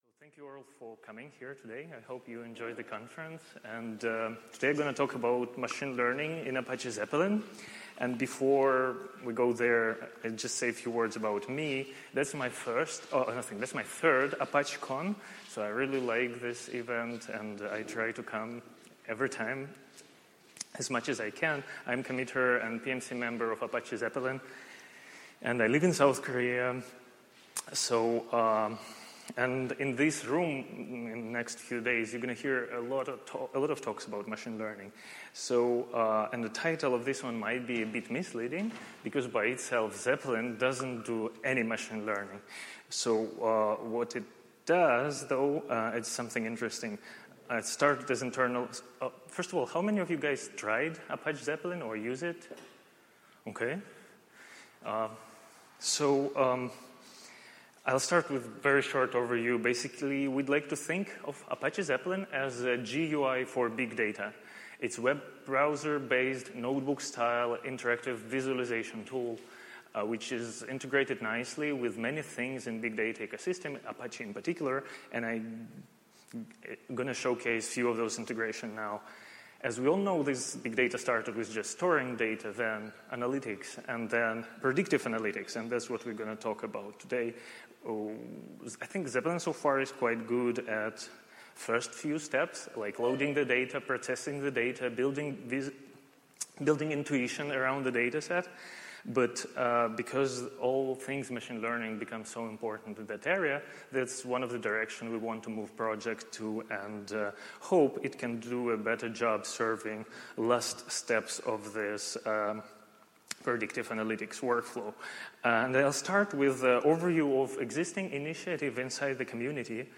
Apache Big Data Seville, 2016